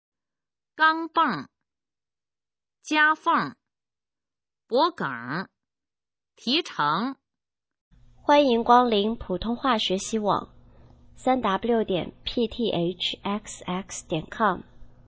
普通话水平测试用儿化词语表示范读音第9部分